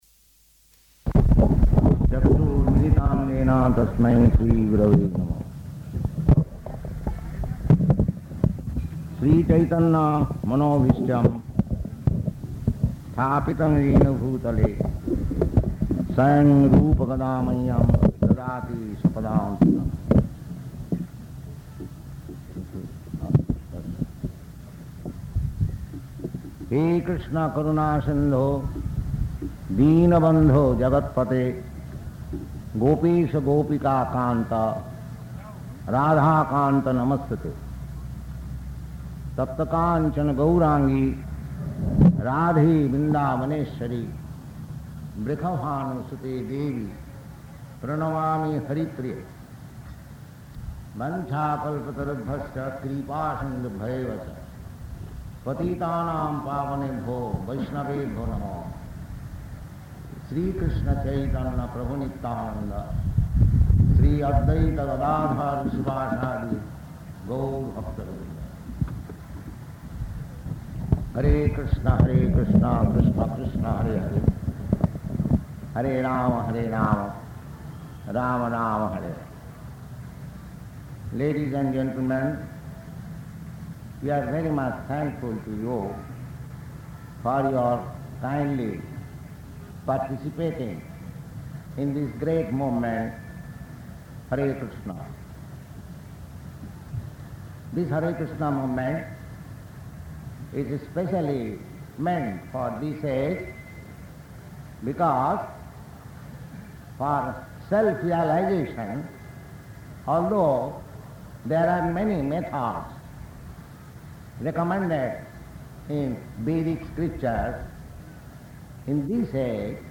Paṇḍāl Lecture
Type: Lectures and Addresses
Location: Bombay
Prabhupāda: [chants maṅgalācaraṇa prayers]